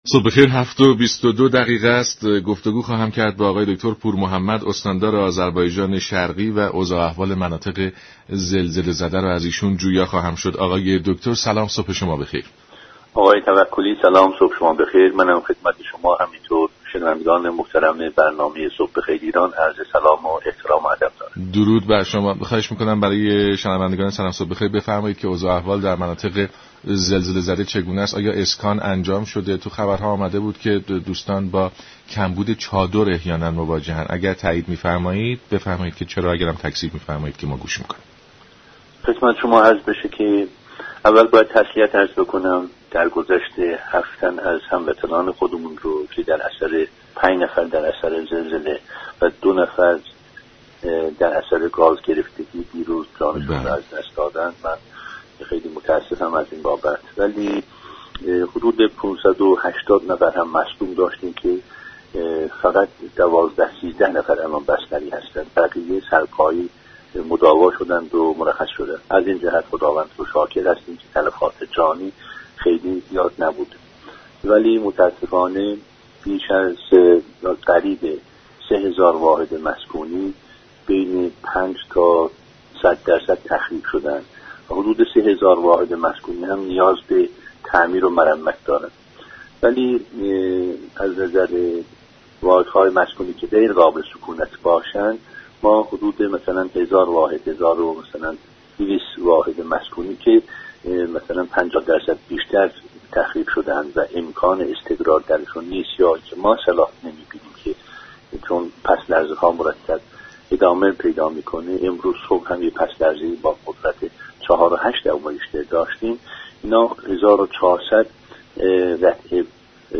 پورمحمدی استاندار آذربایجان شرقی در گفت و گو با رادیو ایران گفت: در پی زمین لرزه روز جمعه بیش از 3 هزار واحد مسكونی بین 5 تا 100 درصد تخریب شده و 3 هزار واحد دیگر نیز نیاز به تعمیر و مرمت دارند.